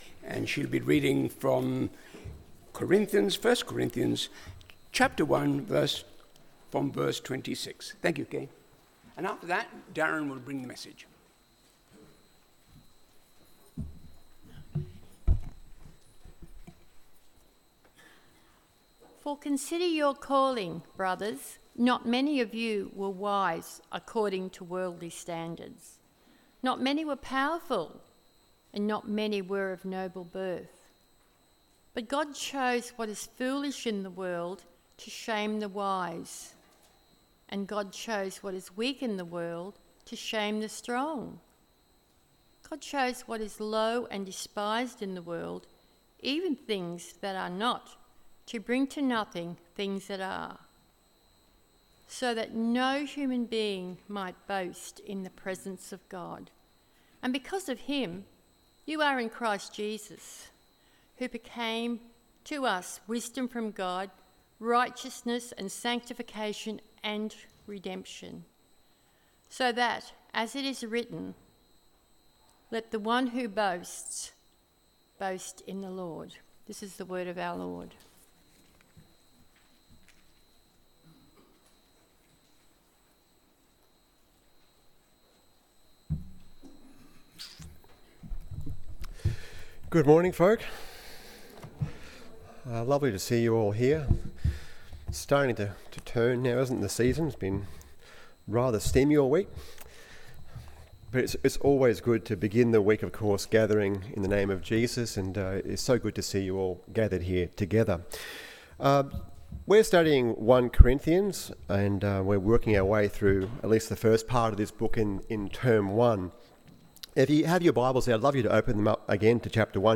A Good Kind Of Boasting AM Service